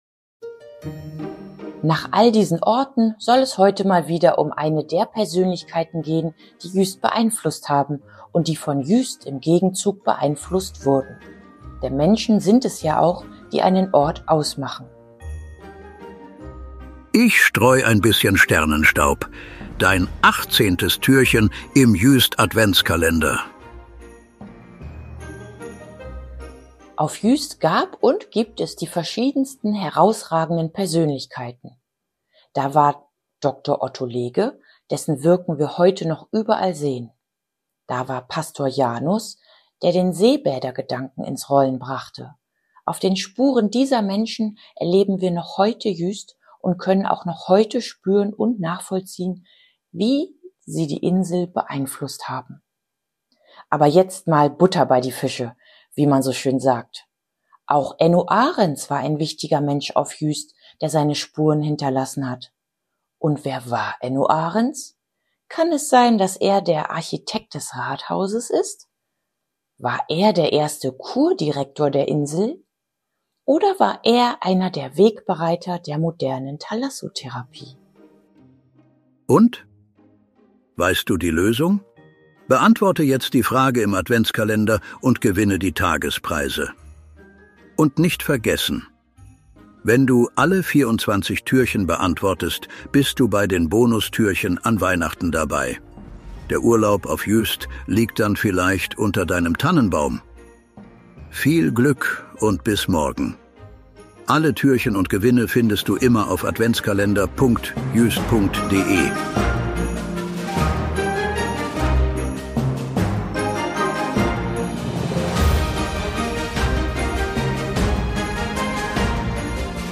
Eingesprochen wird der Adventskalender von vier
guten Geistern der Insel Juist, die sich am Mikro abwechseln und